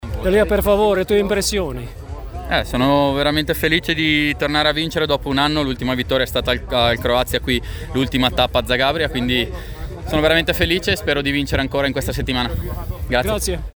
izjavi za Hit radio i portal Ferata Viviani je nakon utrke kratko opisao svoje dojmove: